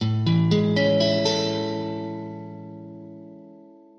xLrn_Amaj7.mp3